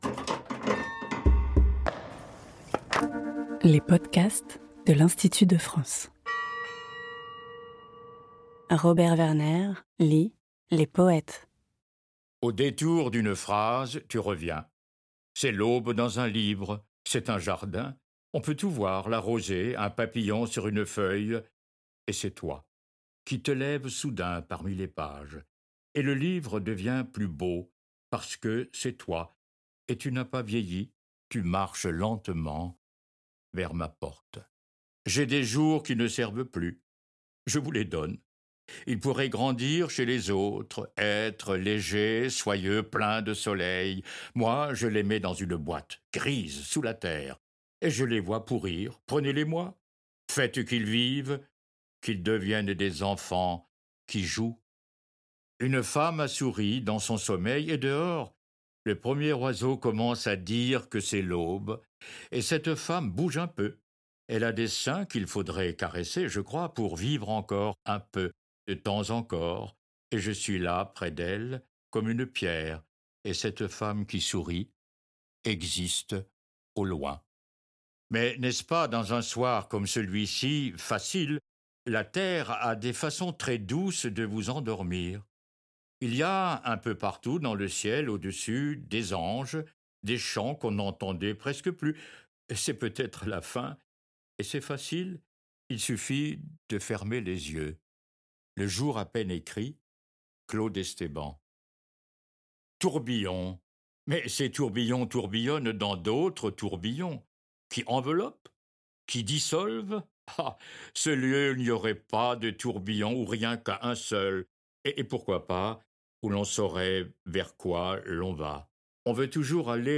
À voix lue